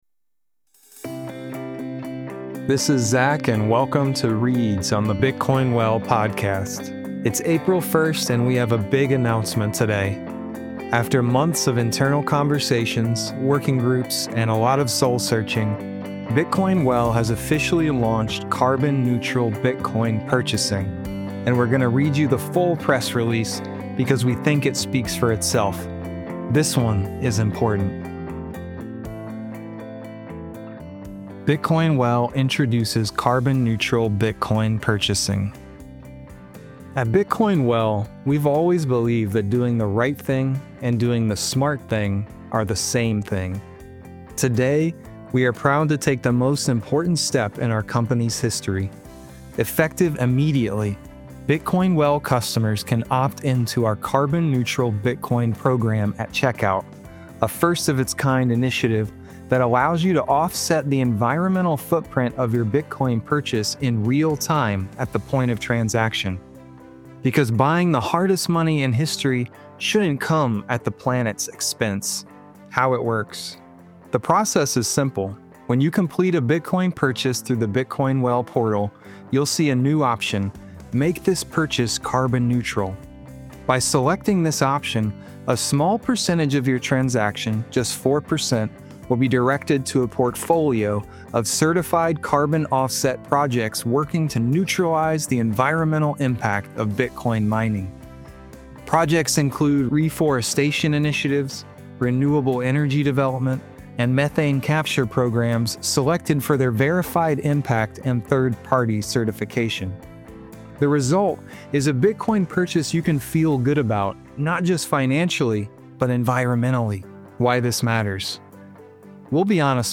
reads the full press release